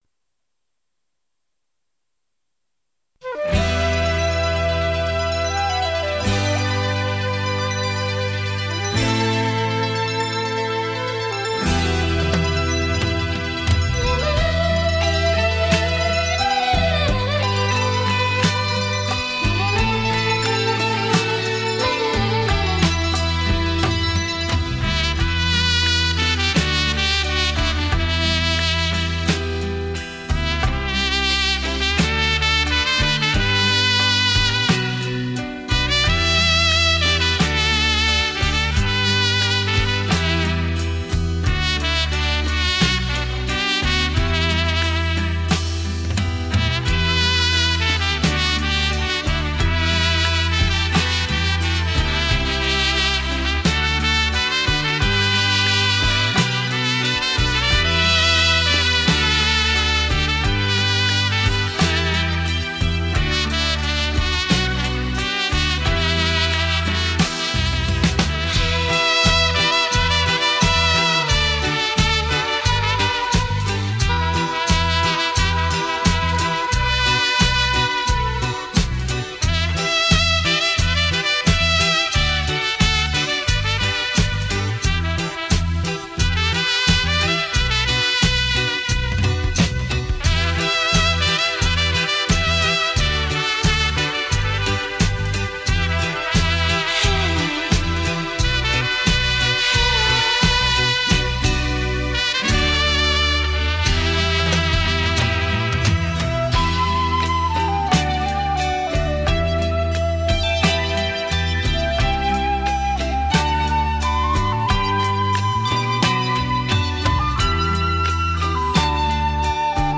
本专辑是根据台语金曲旋律改编的一组小号音乐，激情传神，优美动听的曲调，一定会给你美的享受。